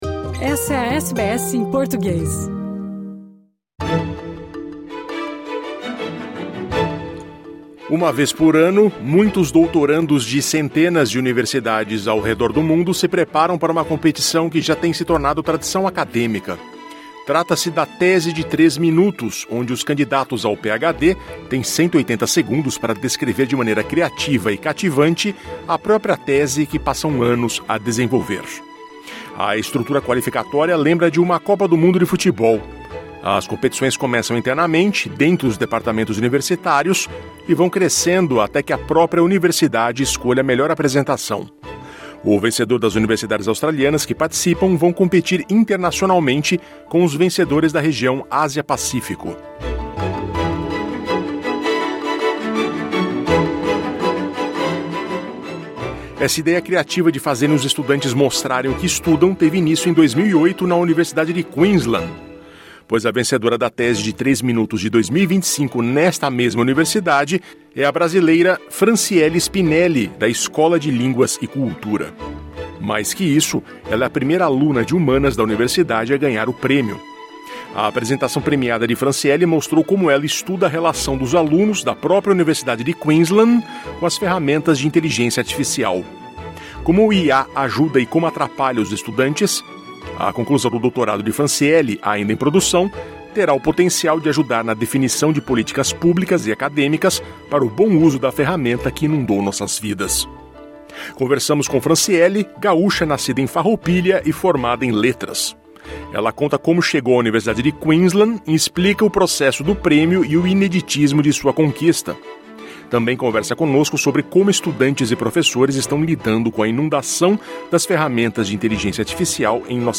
Conversamos com ela sobre a honraria, e também sobre como a IA está facilitando (e atrapalhando) a vida de estudantes e professores no ensino superior.